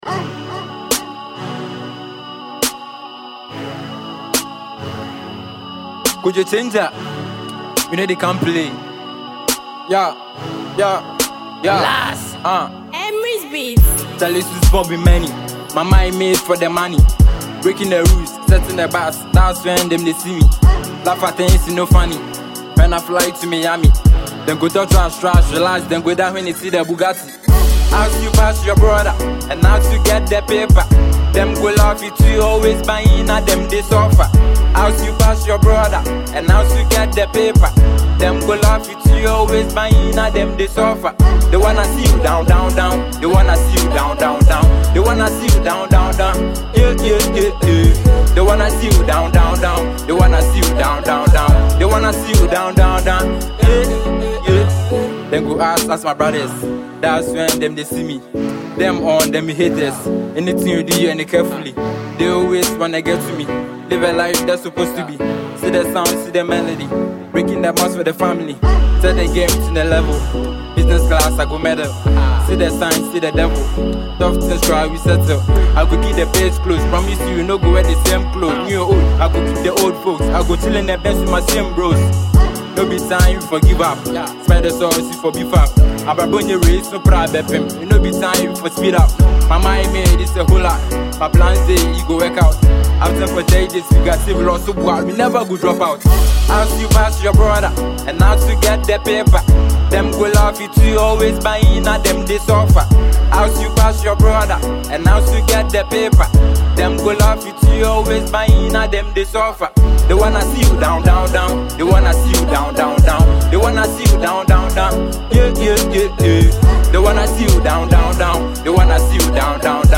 Ghana MusicMusic
dancable tune